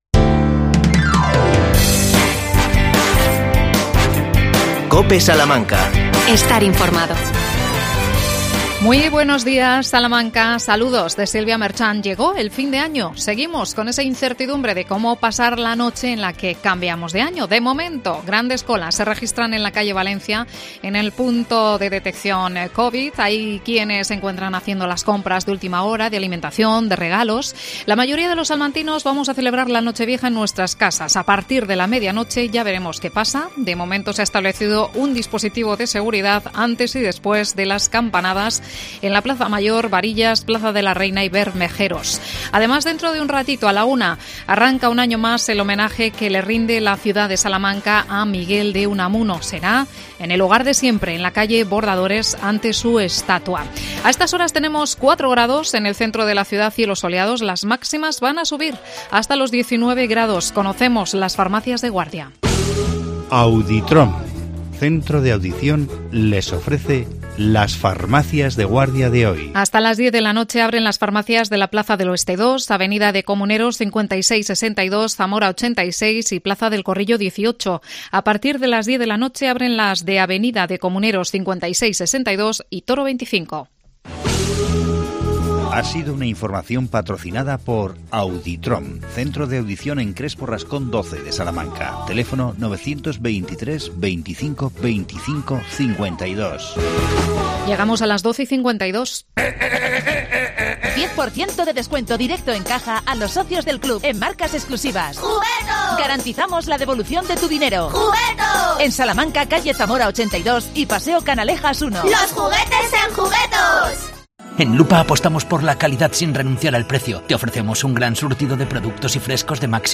AUDIO: La Navidad en Sotoserrano. Entrevistamos a su alcalde Sebastián Requejo.